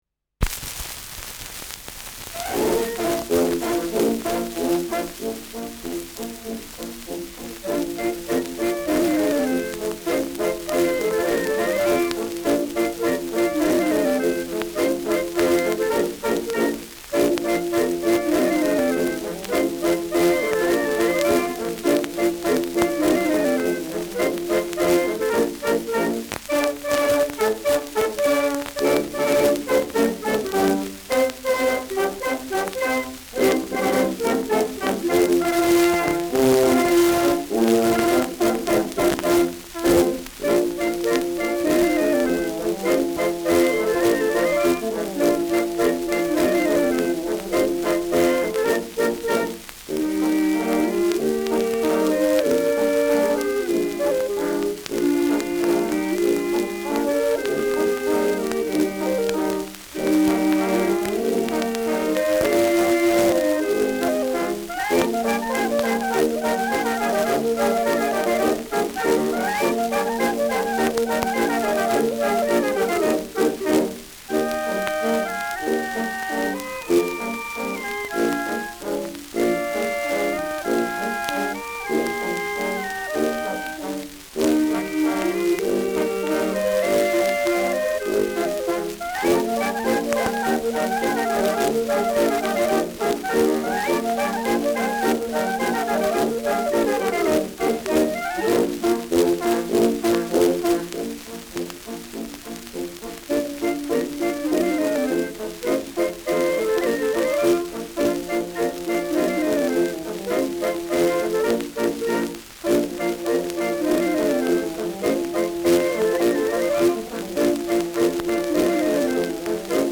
Schellackplatte
leichtes Rauschen